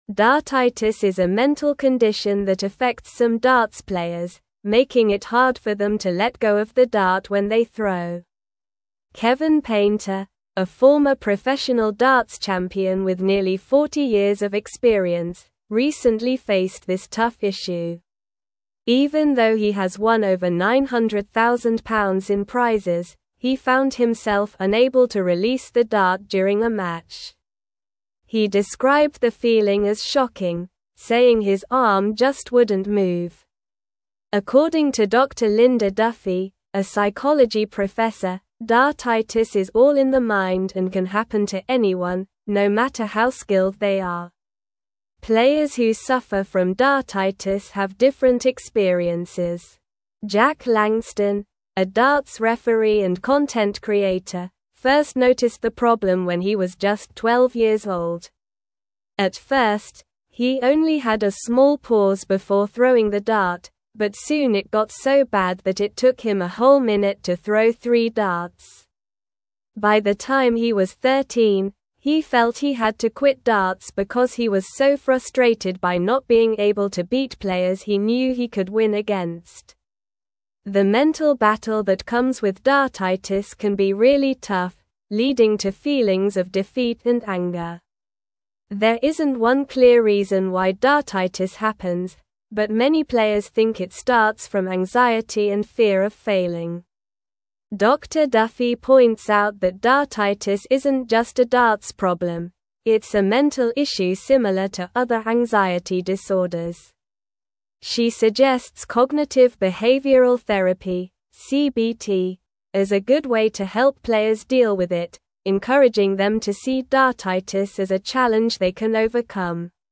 Slow
English-Newsroom-Upper-Intermediate-SLOW-Reading-Overcoming-Dartitis-Athletes-Mental-Health-Challenges.mp3